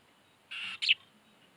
巣内の幼鳥に餌をやるとき
巣内で孵化後しばらくは寝ているヒナに餌をやるとき鳴いて起こすために鳴き、それにこたえてヒナが大きく口を開ける。